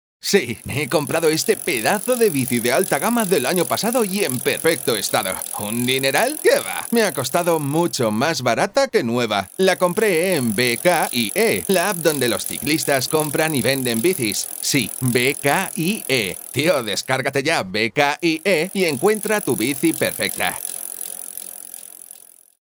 locutor anuncios de radio, locutor publicidad, locutor comercial